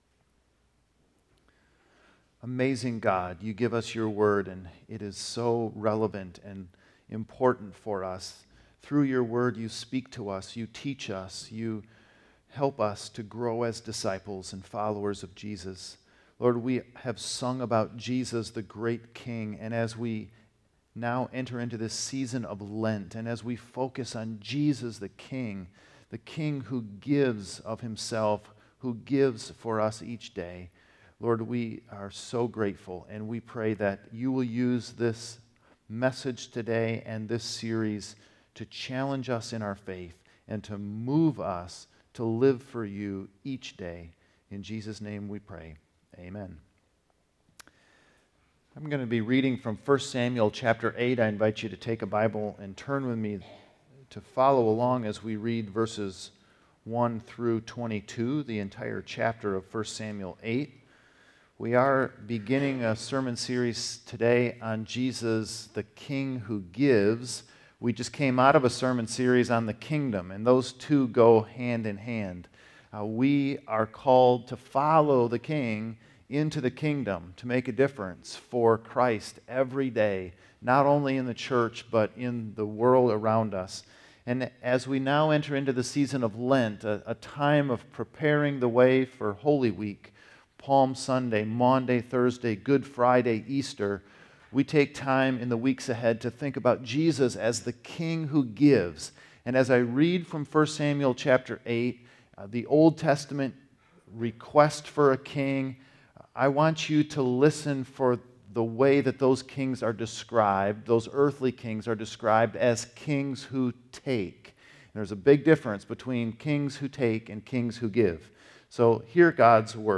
Sermons | Woodhaven Reformed Church